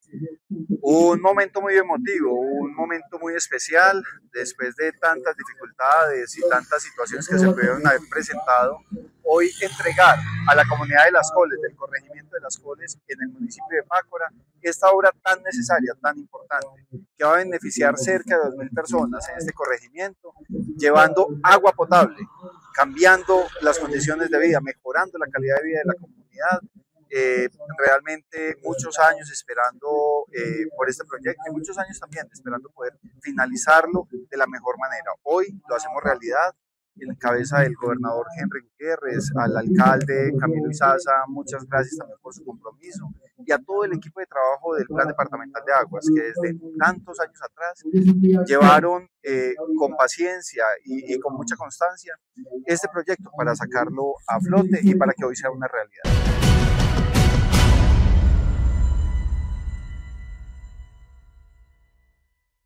Francisco Javier Vélez Quiroga, secretario de Vivienda y Territorio de Caldas